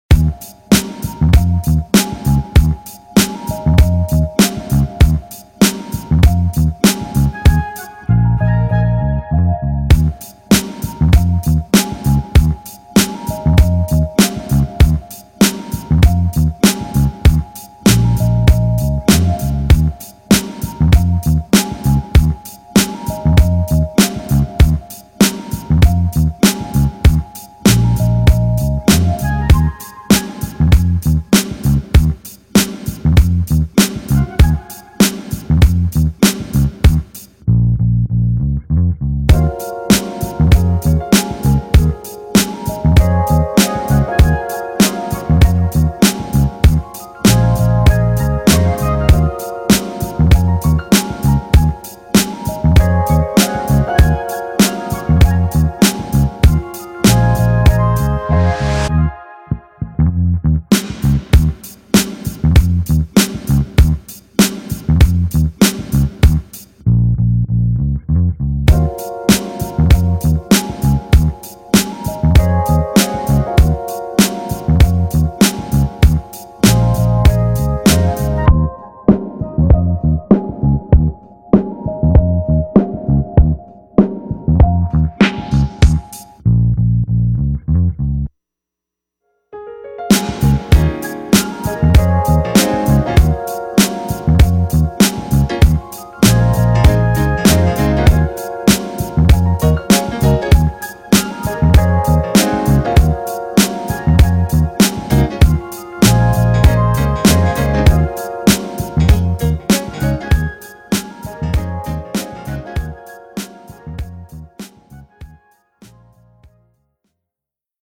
R&B Instrumental